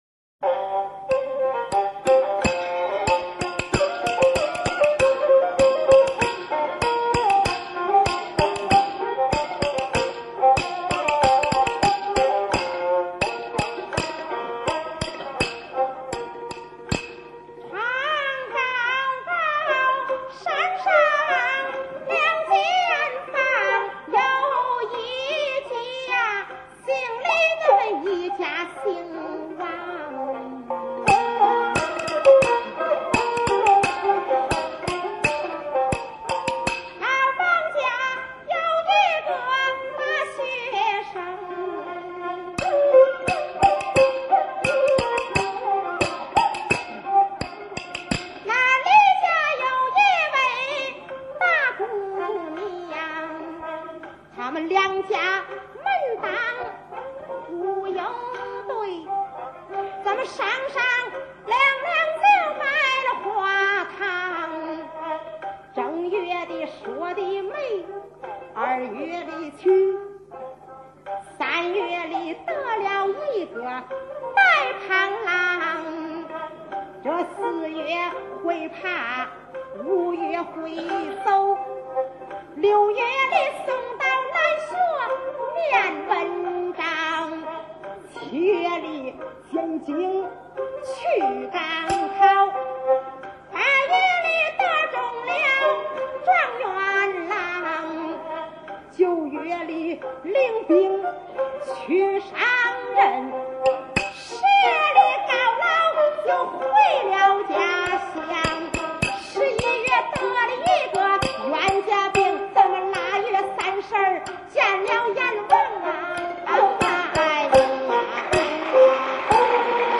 蘊生自民間土壤的說唱藝人
十八段原味酣暢的曲藝聲腔